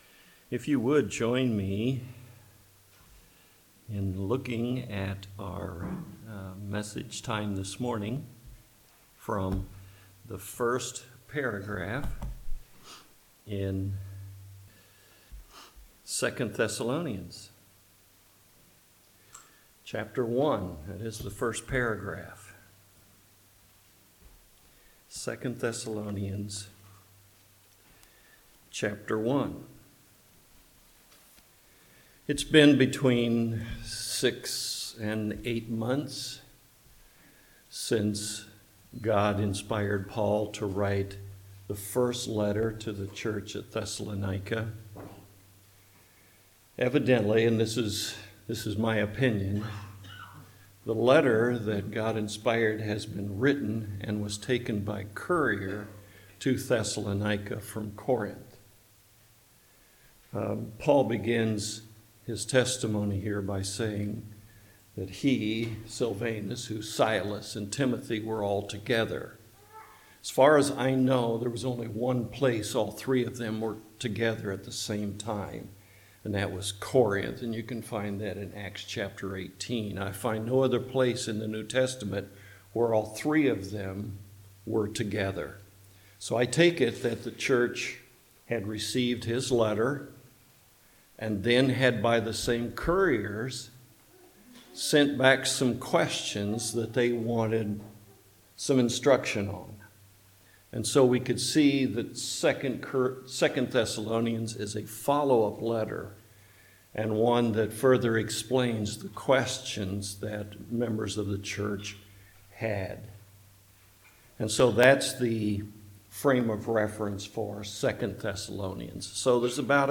2 Thessalonians Passage: 2 Thessalonians 1:1-12 Service Type: Morning Worship « Finally Is There Something Missing Here?